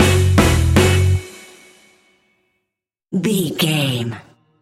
Classic reggae music with that skank bounce reggae feeling.
Aeolian/Minor
F#
laid back
chilled
off beat
drums
skank guitar
hammond organ
horns